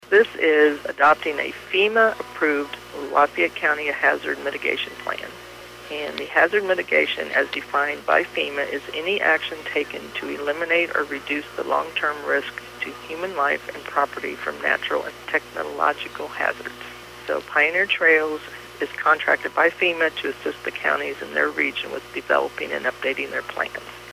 During the meeting of the Higginsville Board of Aldermen on Monday, July 2, aldermen discussed the possible approval of the Lafayette County Multi-Jurisdictional Hazard Mitigation Plan. City Administrator Jeanette Dobson explains what that is….